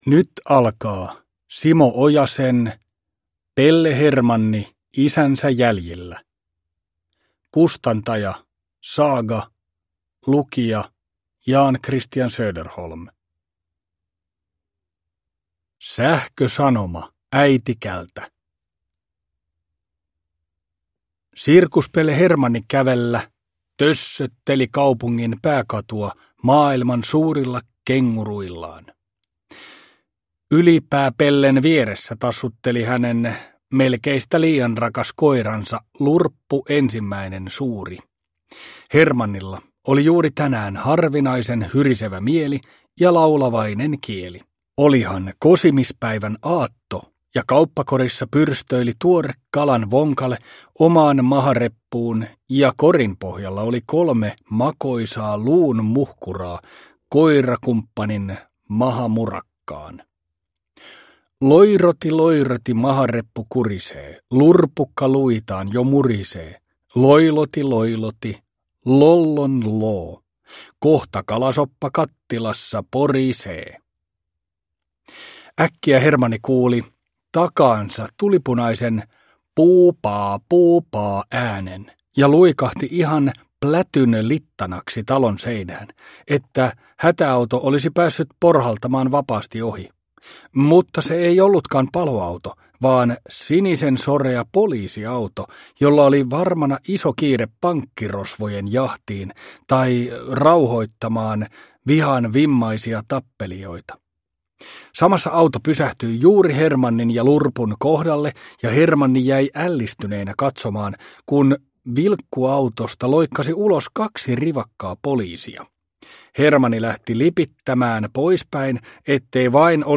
Pelle Hermanni isänsä jäljillä – Ljudbok – Laddas ner